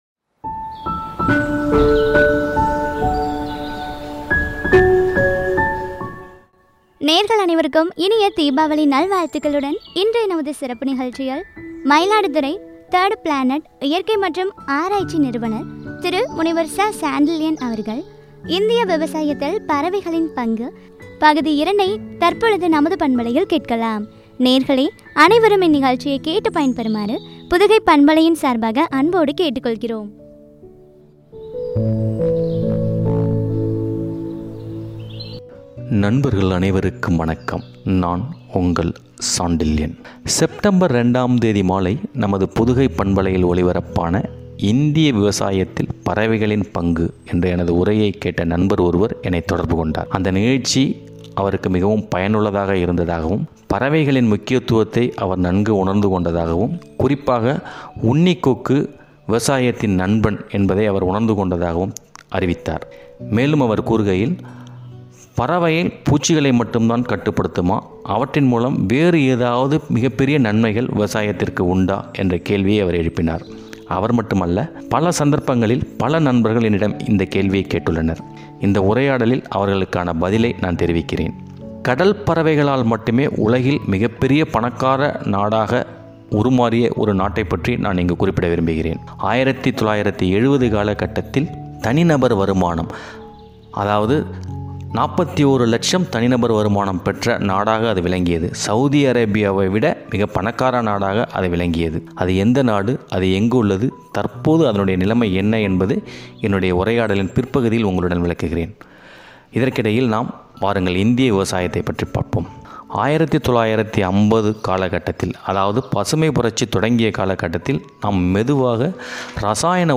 பற்றிய உரையாடல்.